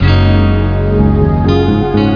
Guitare.wav